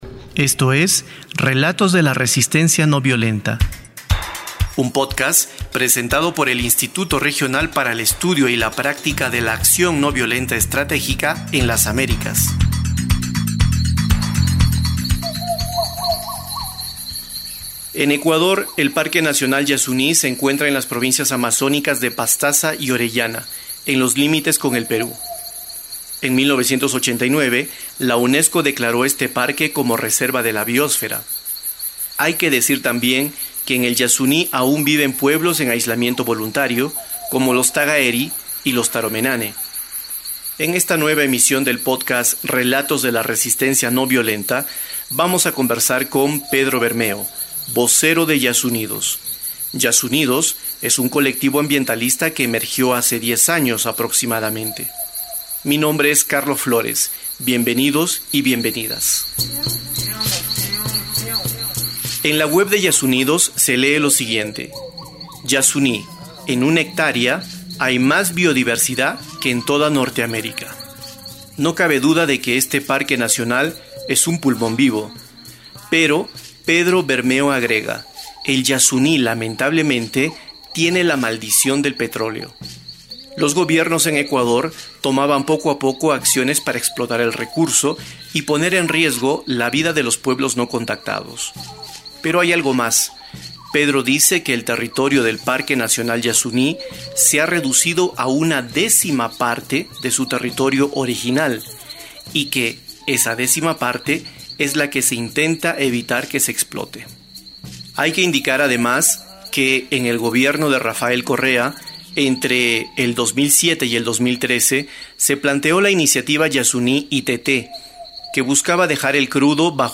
Tamaño: 18.74Mb Formato: Basic Audio Descripción: Entrevista - Acción ...